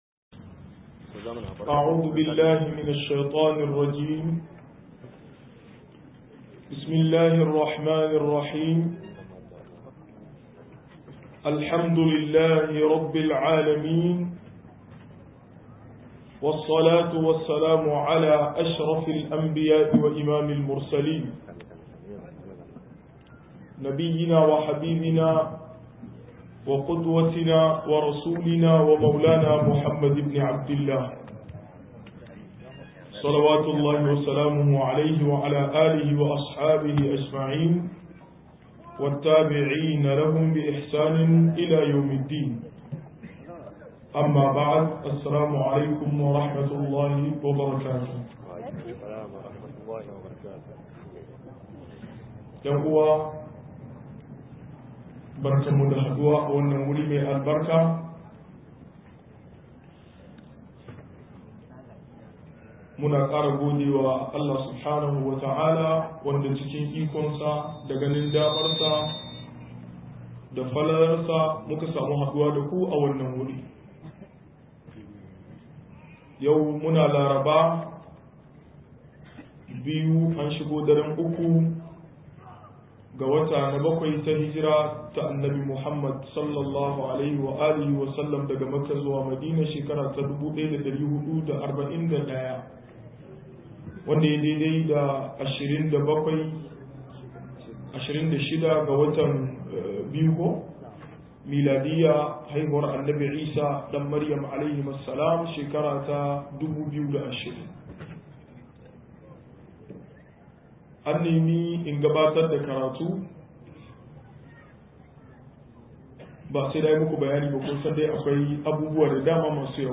68-ababenda ke hana samun ilimi - MUHADARA